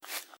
Grass Step 05.wav